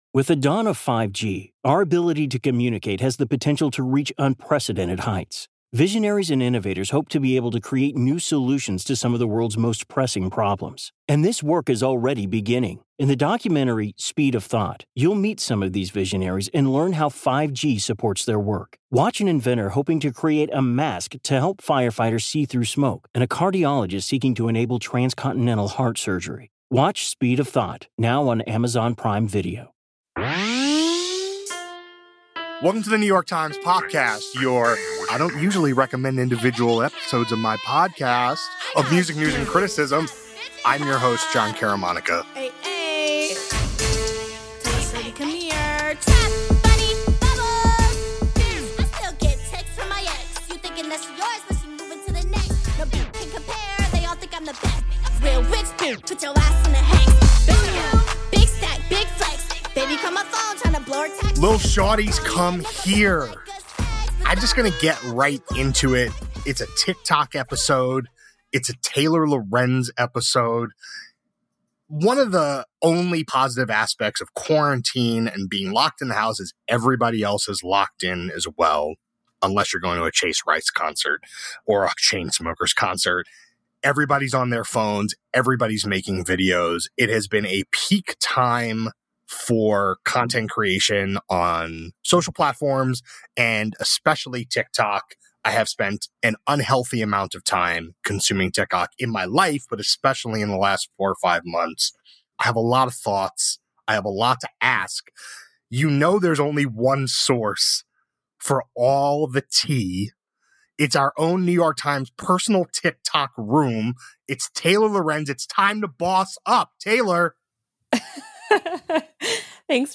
A conversation about how TikTok’s relationship to music has evolved over the last 12 months.
But its relationship to the business is far more complex. Guest: Taylor Lorenz.